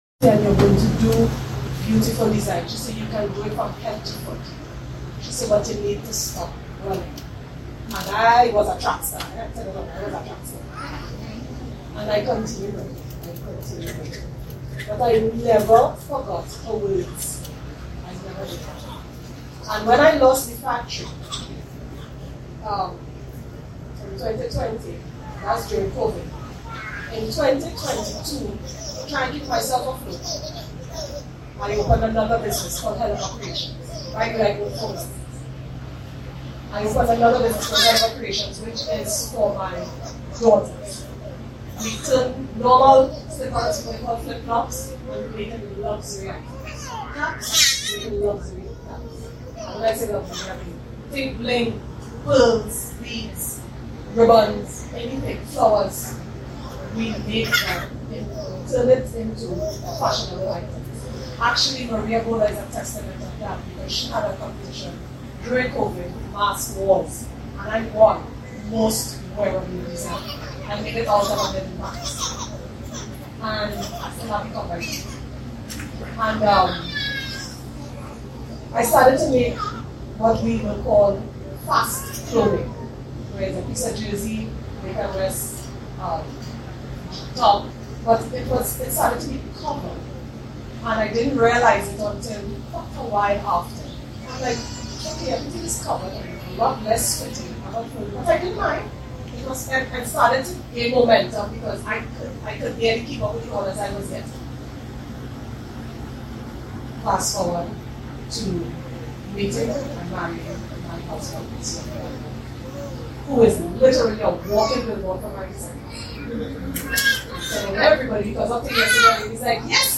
testimony at Fashion for a Mission 2